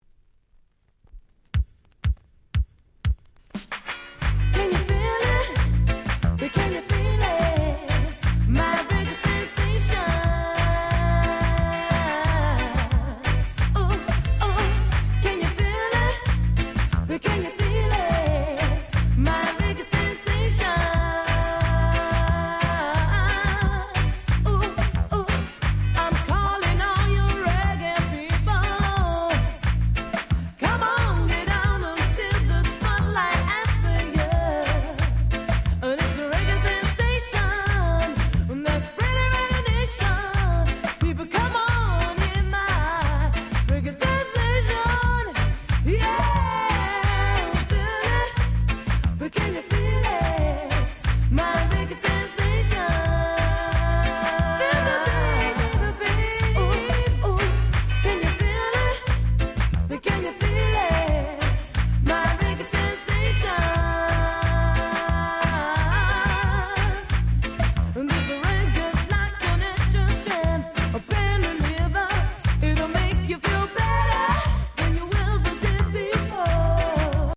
Very Rare Lovers Reggae !!